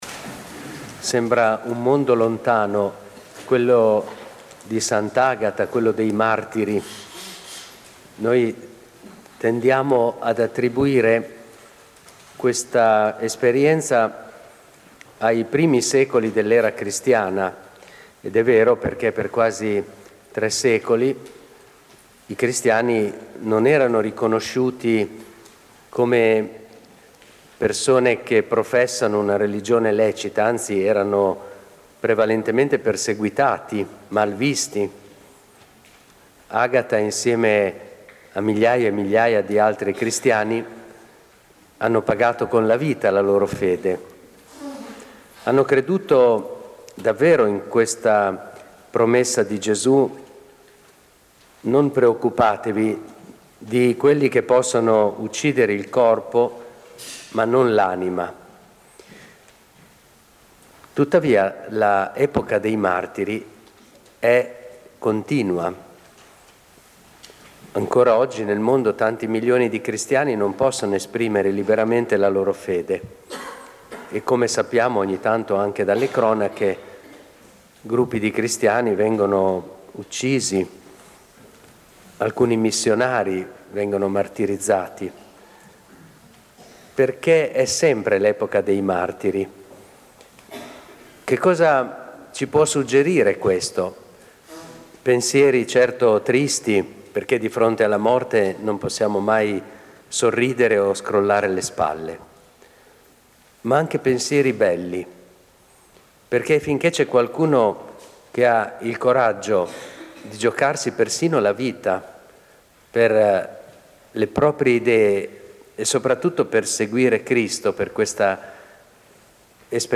Durante la celebrazione presieduta dal vescovo Erio Castellucci sono stati ordinati anche tredici ministri straordinari dell'Eucarestia.
In una chiesa della Santissima Trinità gremita è stata celebrata questa mattina alle 10.30 la Messa di Sant’Agata, patrona della parrocchia di Cibeno.
L’omelia completa
Omelia-vescovo-per-s.-agata.mp3